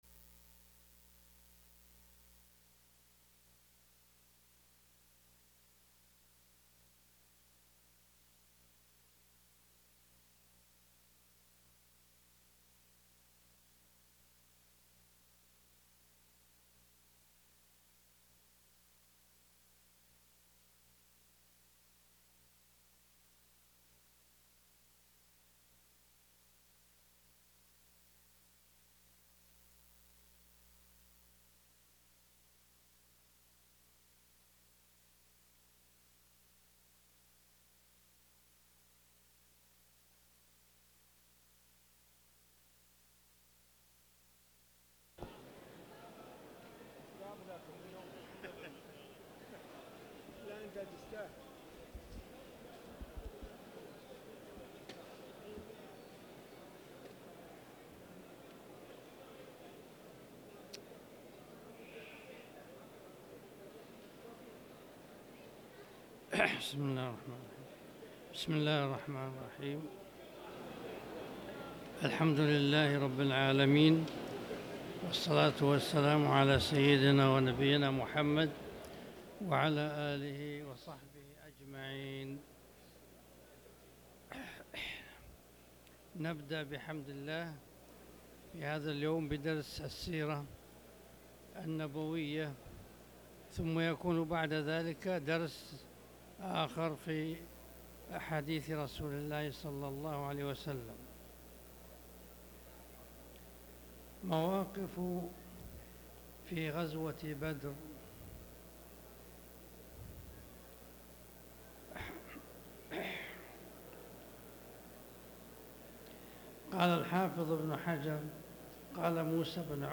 تاريخ النشر ١٥ ربيع الأول ١٤٣٩ هـ المكان: المسجد الحرام الشيخ